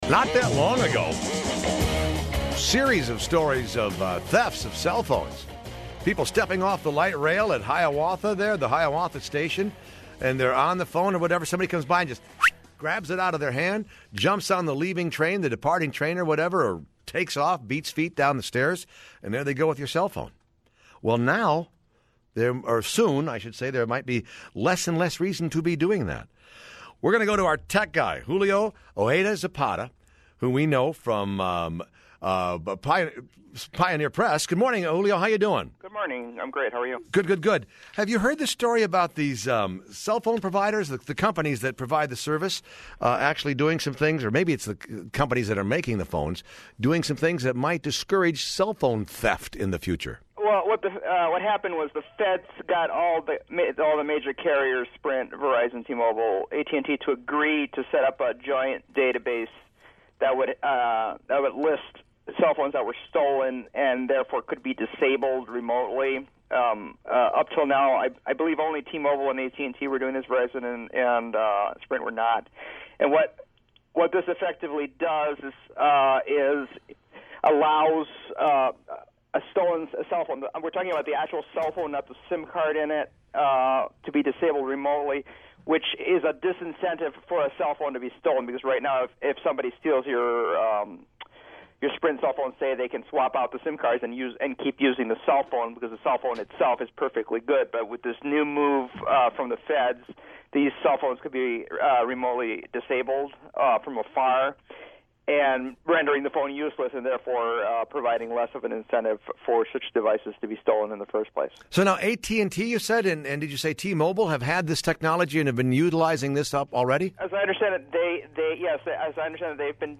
I talk cell phones on WCCO-AM